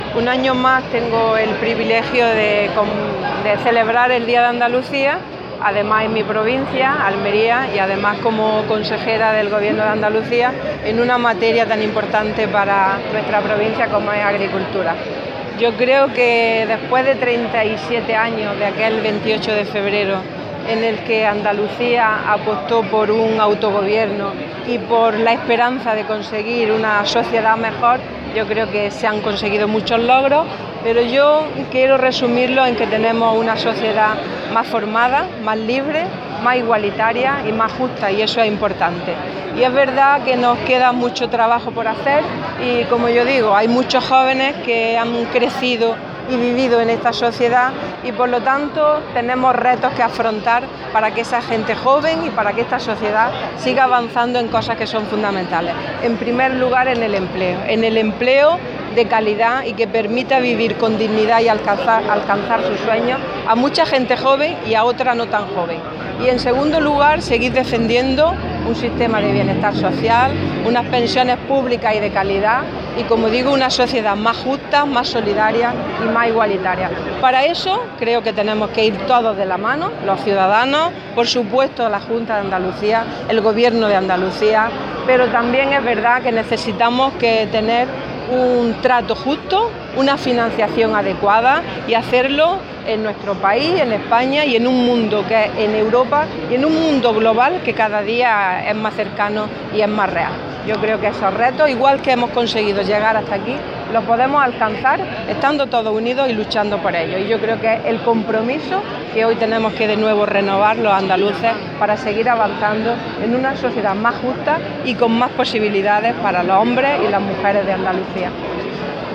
La consejera de Agricultura ha presidido en Almería el acto de entrega de Insignias y Banderas en la provincia con motivo del 28F
Declaraciones consejera 28F